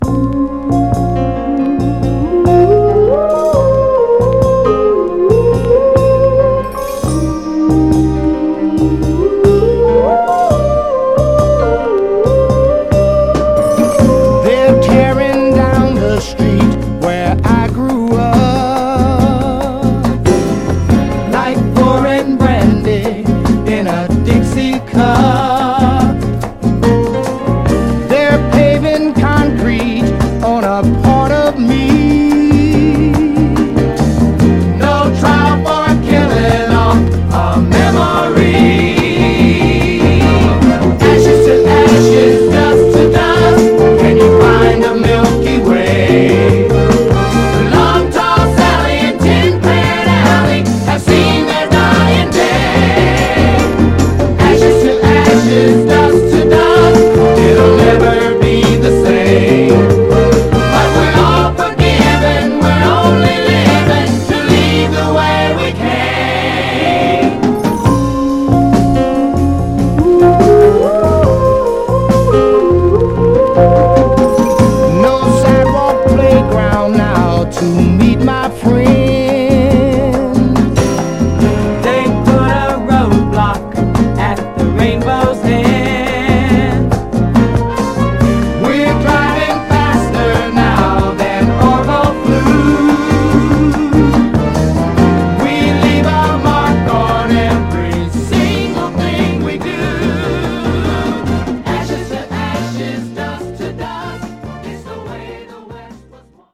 ドープなイントロがカッコいいですが、それよりもこみ上げ系の高揚メロディが心に染みる、すごく美しい曲でオススメです。
※試聴音源は実際にお送りする商品から録音したものです※